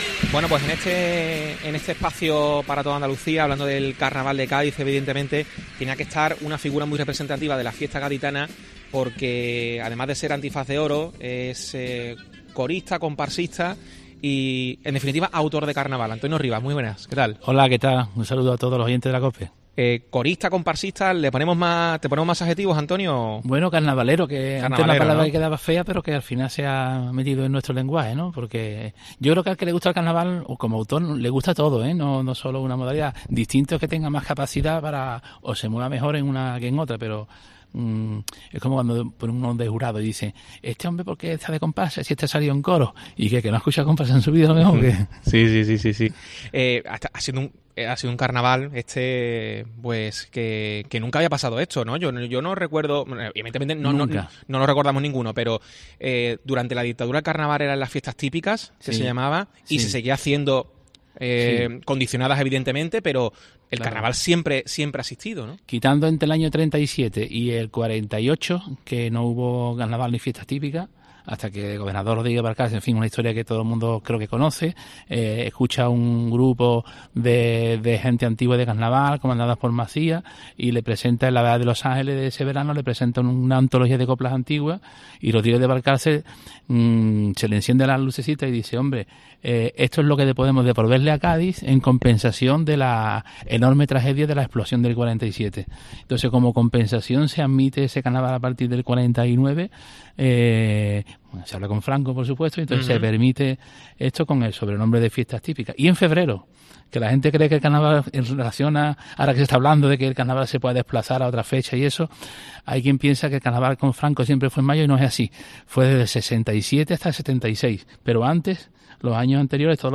Corista, comparsista y Antifaz de Oro de la fiesta gaditana, el gaditano habla de Carnaval en los micrófonos de COPE desde el Gran Teatro Falla.